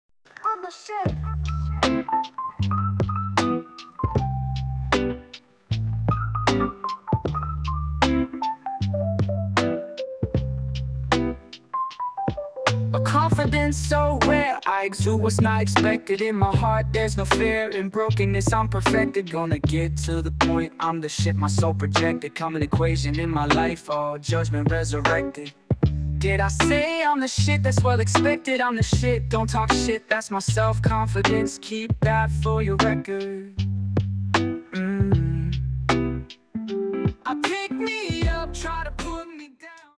An incredible Funk song, creative and inspiring.